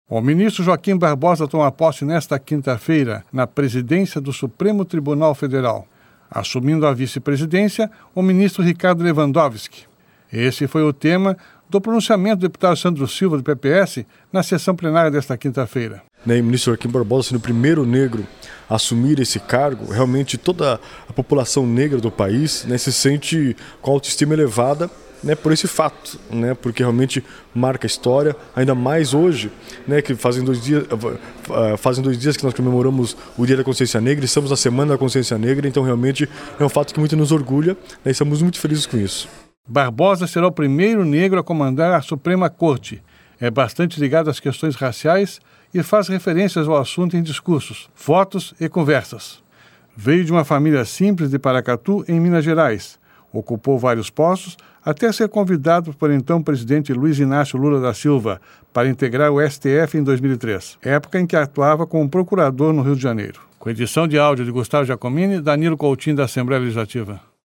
O ministro Joaquim Barbosa toma posse  nesta quinta-feira (22) na presidência do Supremo Tribunal Federal (STF), assumindo a vice-presidência o ministro Ricardo Lewandowski. Esse foi tema do pronunciamento do deputado Sandro Silva (PPS) na sessão plenária desta quinta-feira (22).